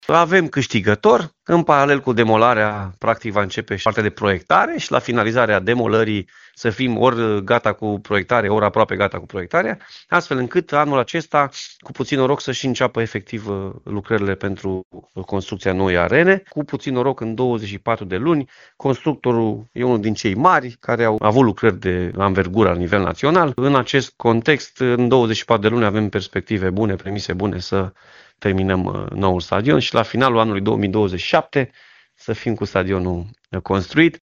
Potrivit președintelui Consiliului Județean Timiș, Alfred Simonis, numele constructorului va fi făcut public în câteva zile, după expirarea termenului de depunere a contestațiilor.